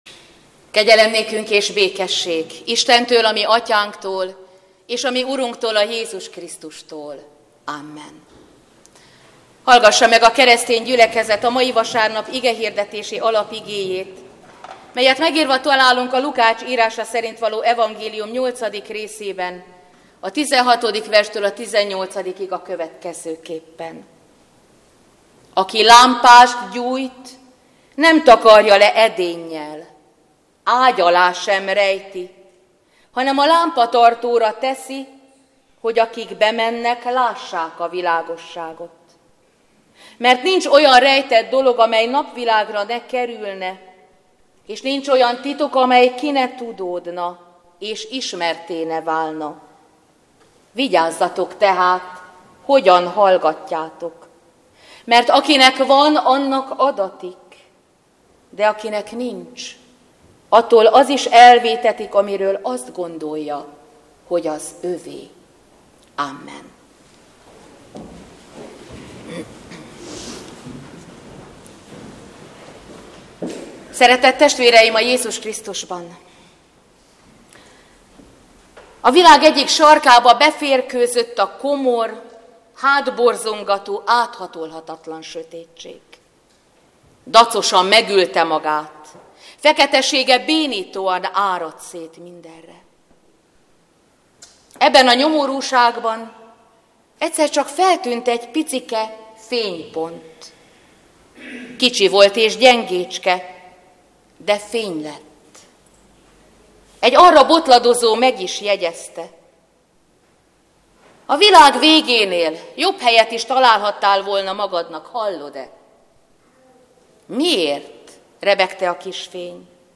Szentháromság ünnepe után 5. vasárnap - Kegyelemből van üdvösségetek hit által, és ez nem tőletek van: Isten ajándéka.